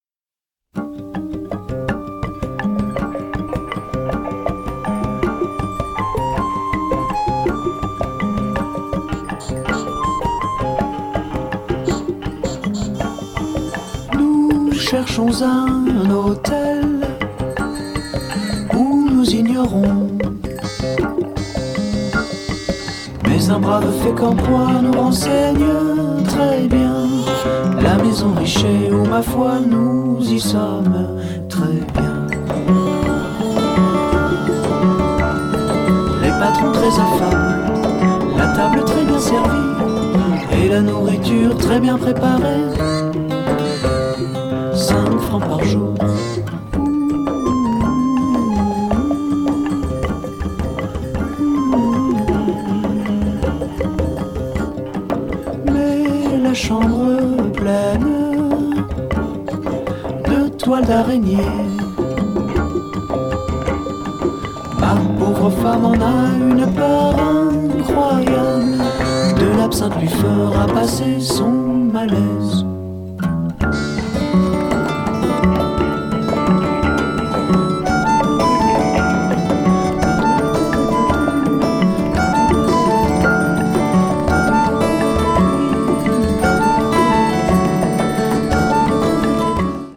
風通しの良い見事なアヴァン・シャンソンです！
シンプルでありながらも音の仕掛けが多いので何度も聴きたくなるような魅力がありますよ！